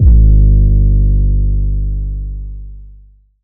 DDW2 808 1.wav